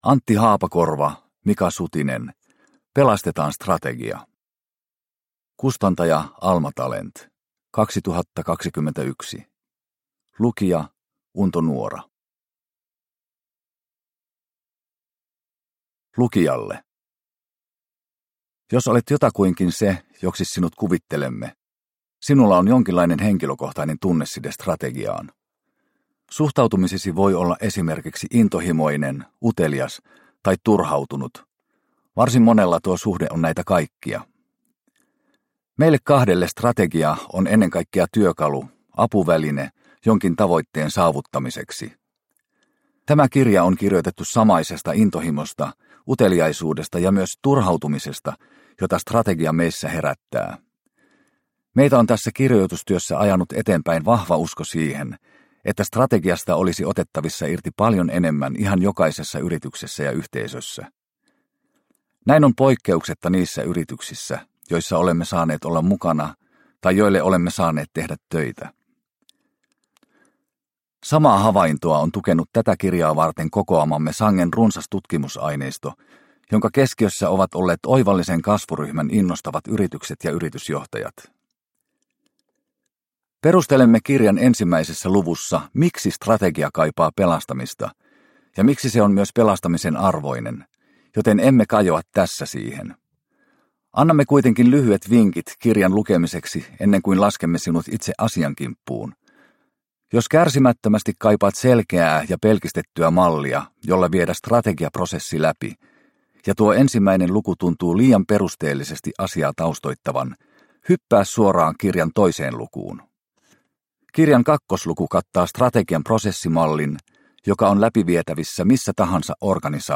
Pelastetaan strategia! – Ljudbok – Laddas ner